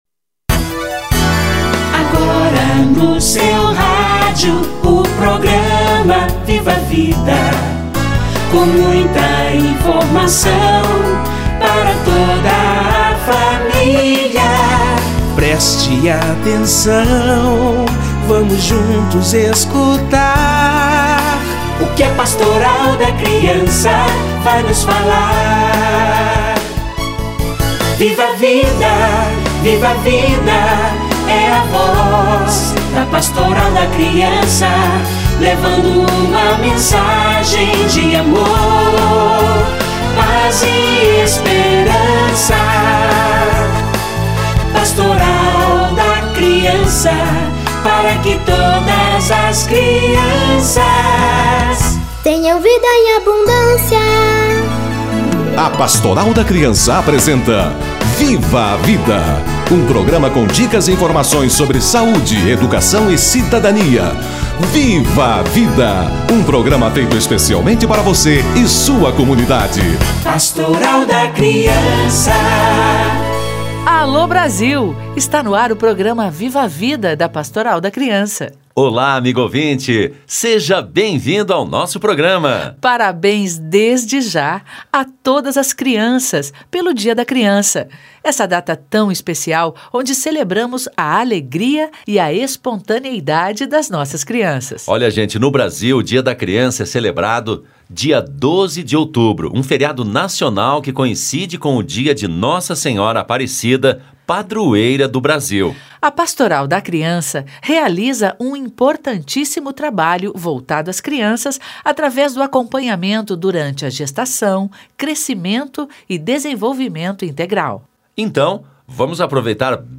Dia da Criança e prevenção da obesidade - Entrevista